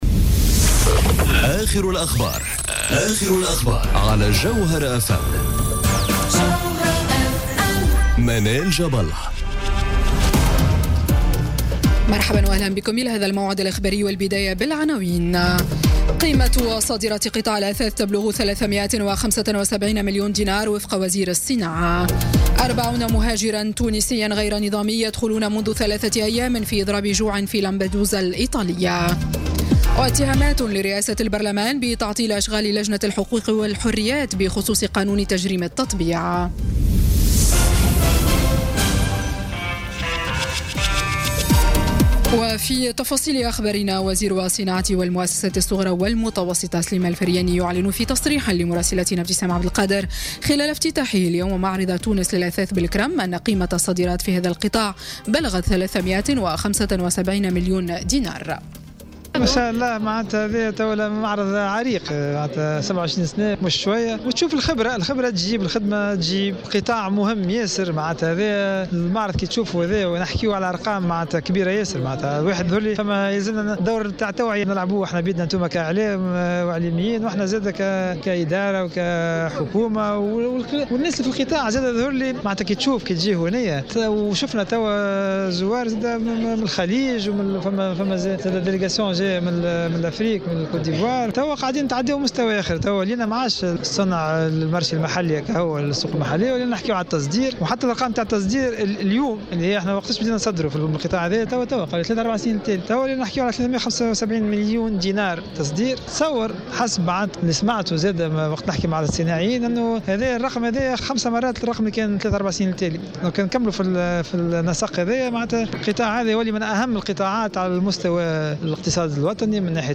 Journal Info 19h00 du Vendredi 26 Janvier 2018